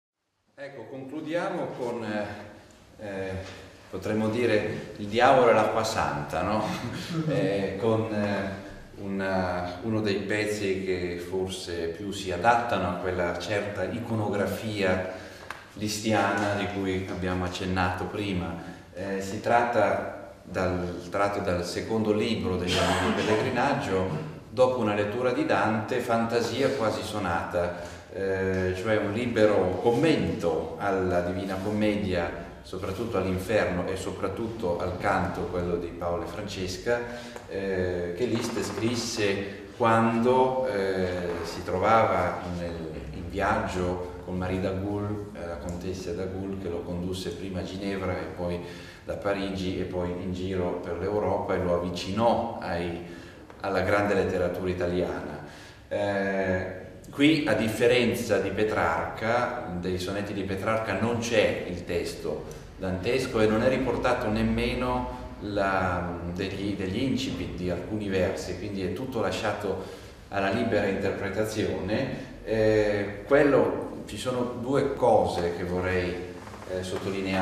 IV OTTOBRE MUSICALE A PALAZZO VALPERGA
pianoforte